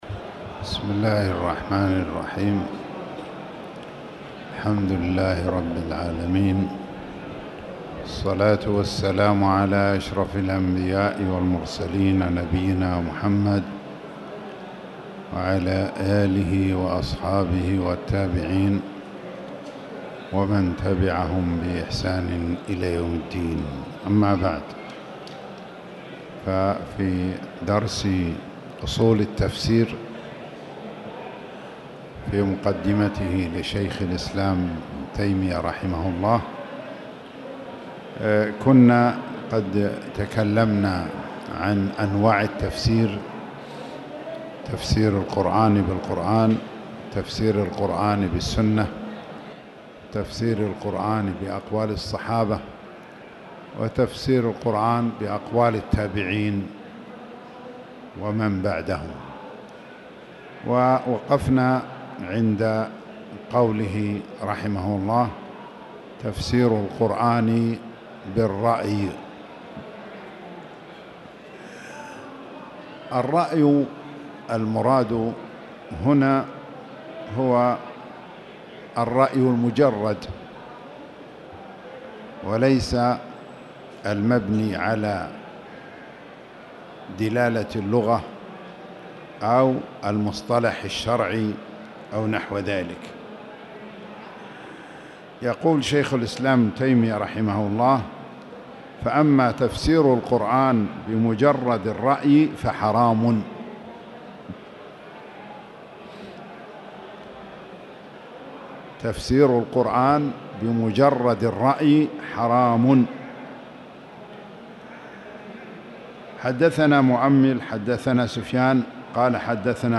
تاريخ النشر ٩ محرم ١٤٣٨ هـ المكان: المسجد الحرام الشيخ